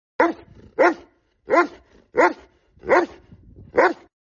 Sound Effect - Dog Deep Barking 01 Sound Button - Free Download & Play